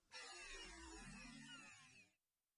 Energy Down.wav